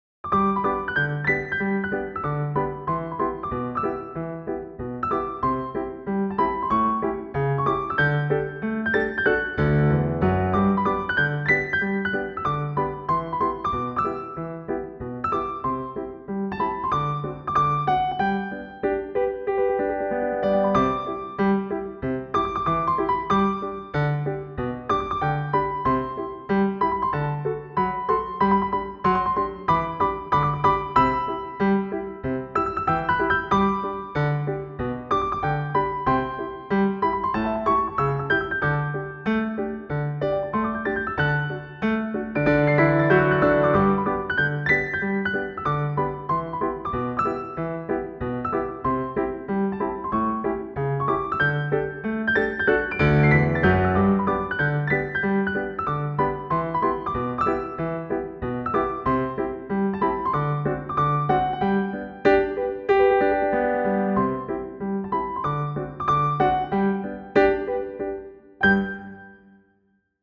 言うほどわちゃわちゃしてなくてお上品が漏れてるかもしれない。
-日常系